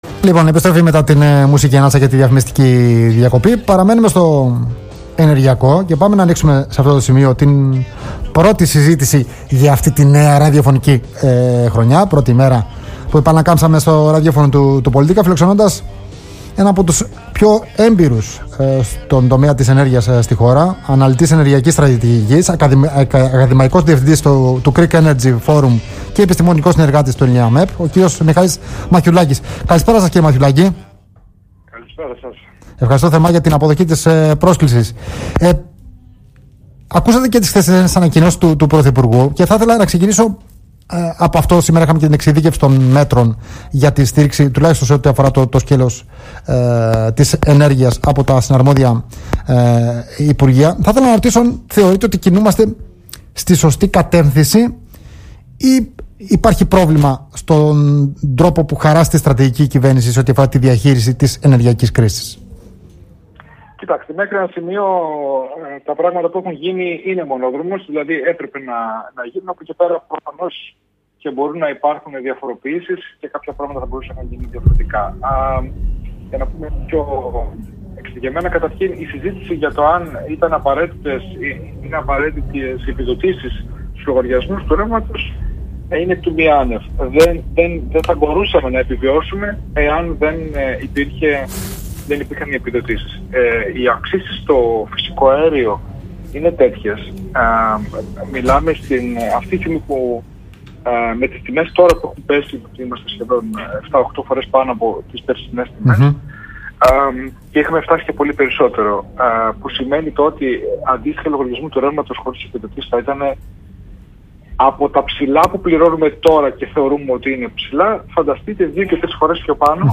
Την πεποίθηση πως τα χειρότερα έχουν περάσει στις τιμές ενέργειας εφόσον δεν υπάρξει κάποια θεαματική ανατροπή εξέφρασε μιλώντας στον Politica 89.8 και